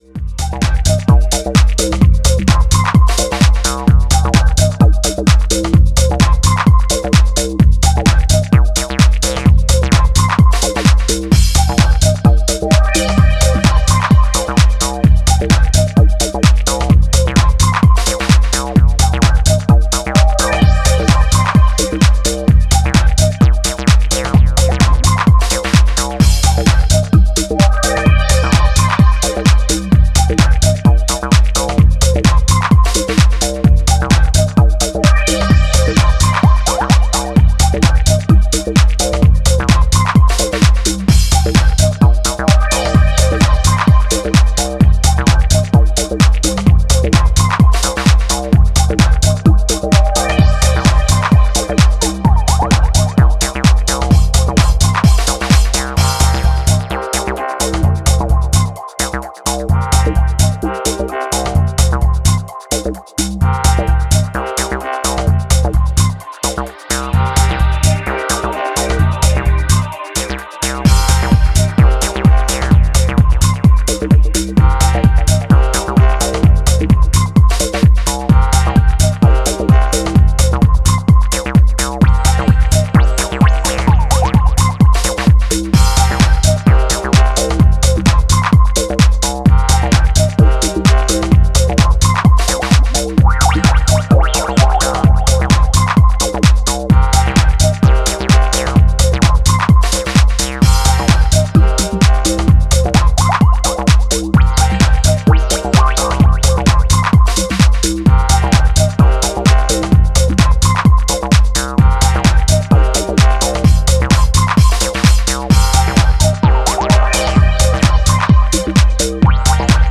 ここでは、流麗なシンセワークや推進力溢れるグルーヴを駆使したクールなミニマル・テック・ハウスを展開しています。